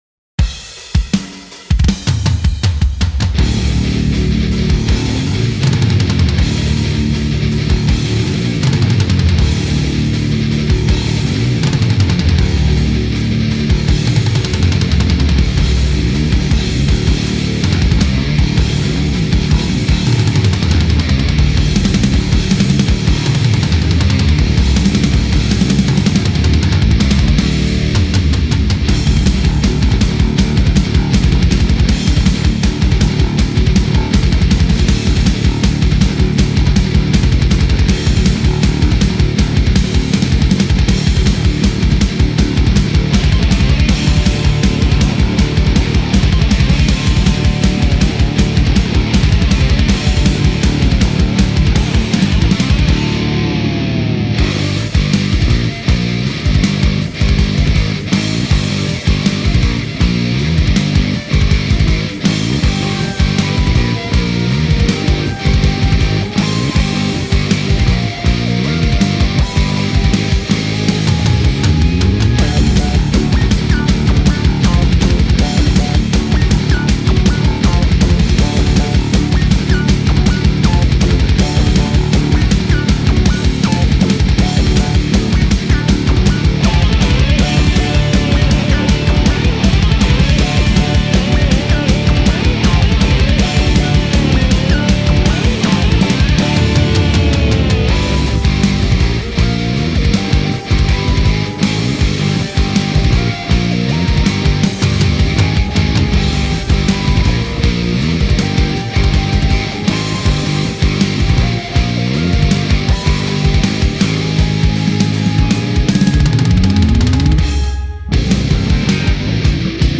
Что по звуку гитарному?
Писал через НХ стомп. Решил отмастерить в Озоне 8 Эл.
Середину плагин поднял.
То ли ОК, то ли ту мач середины?